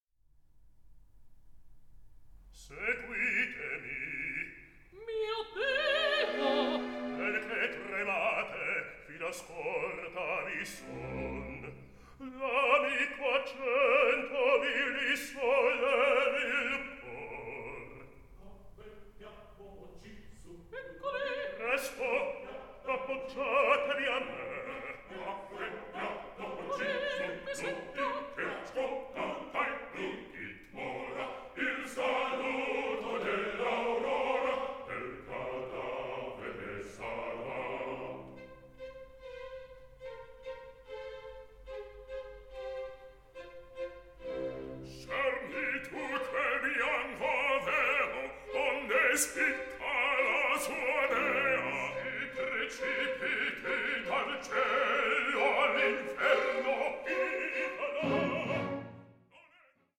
resulting in a performance that is lively and balanced.